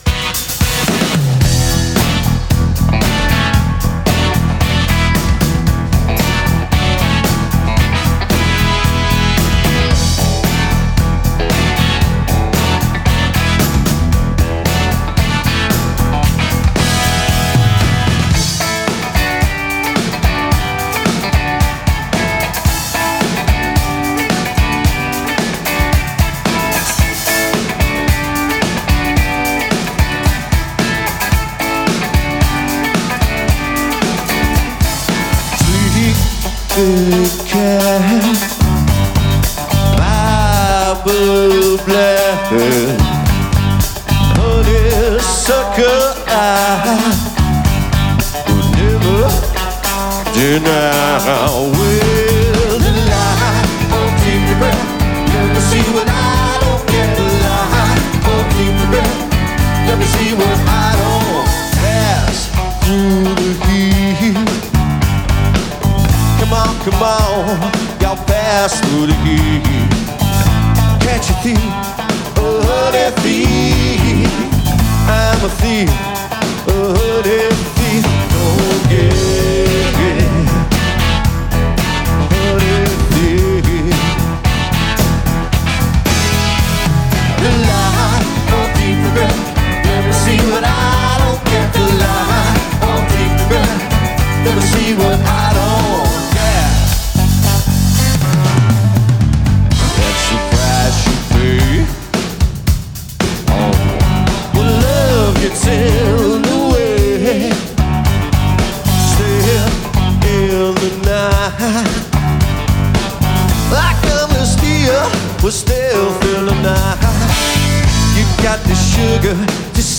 A taste of Blue-eyed Soul from Scotland tonight
an intoxicating blend of pop/rock and soul